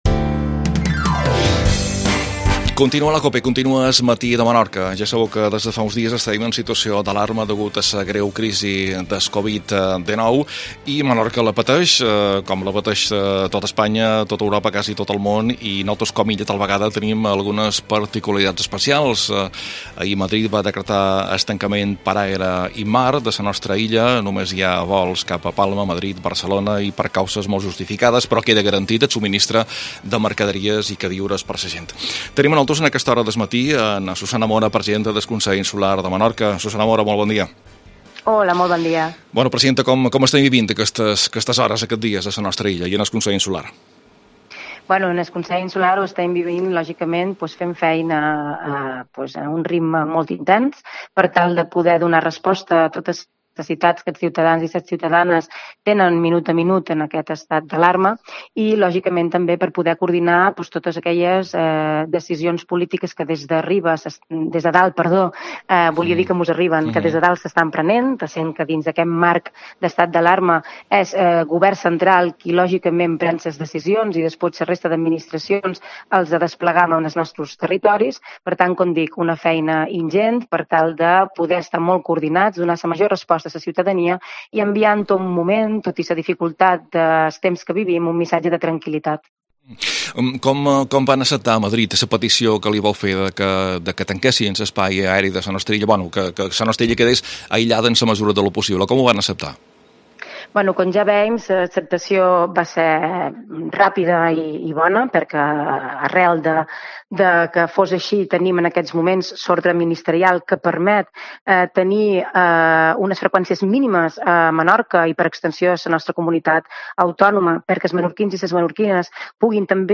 AUDIO: Entrevista a Susana Mora, presidenta del Consell Insular de Menorca